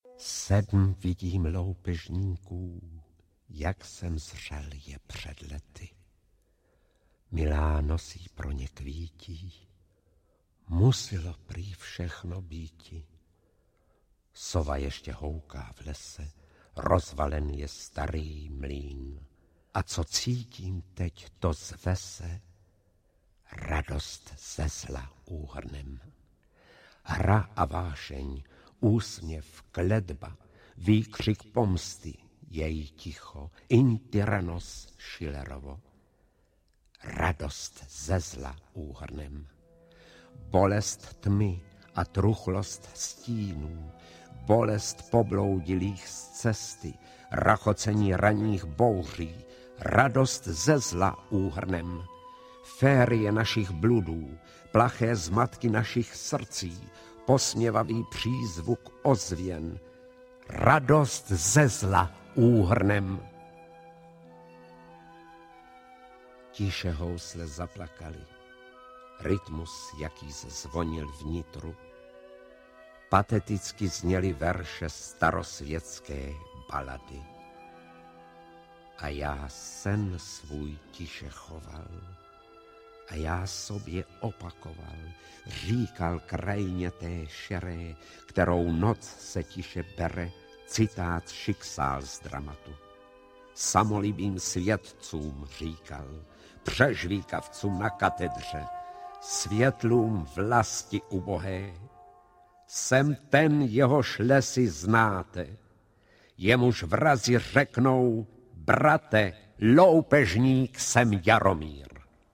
Milá sedmi loupežníků audiokniha
Ukázka z knihy